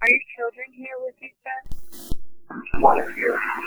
Class A EVP's
at the Houghton Mansion.